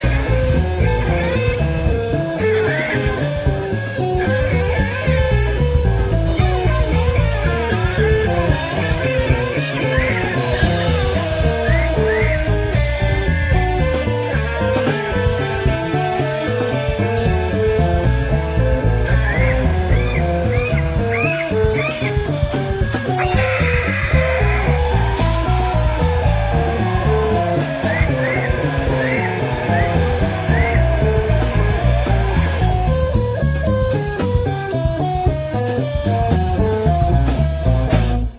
rock instrumental - math